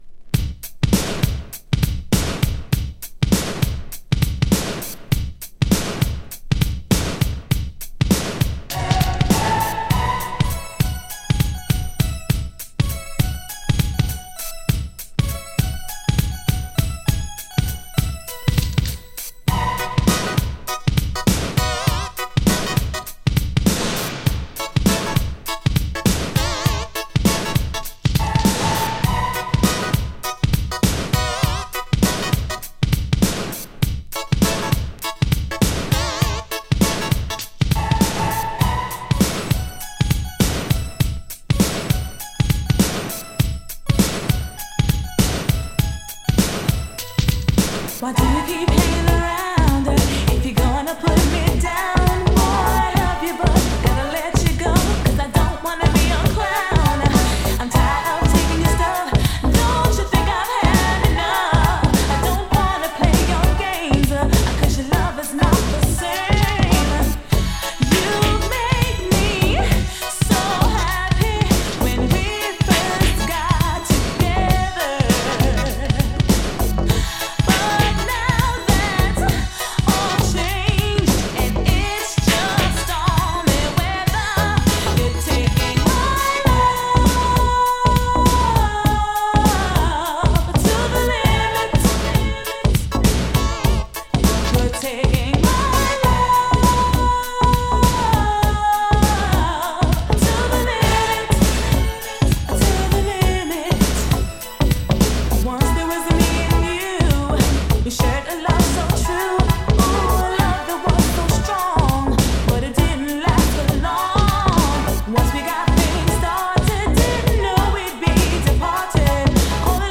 [NEW JACK SWING]